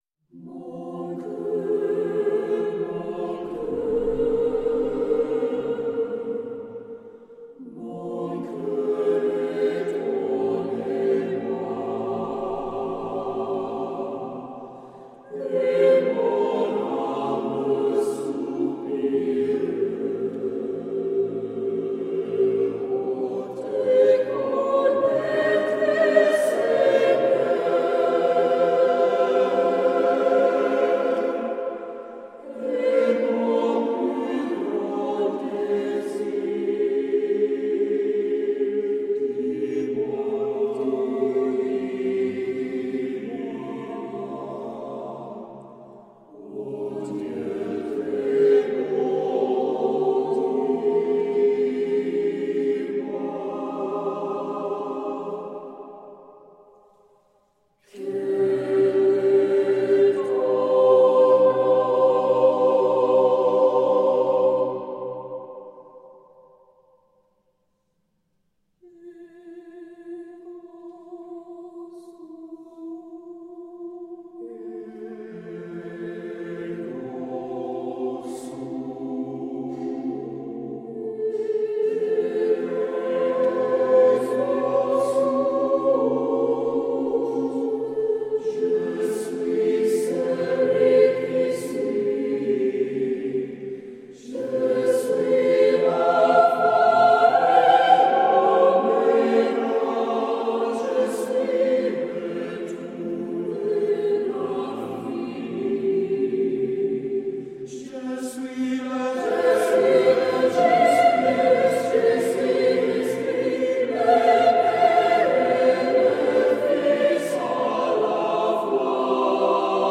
While executing both small and large scale productions, the goal for YPAS has always been to highlight the achievements of these young musicians.
• Tags Audio, Classical, Recorded Live, Video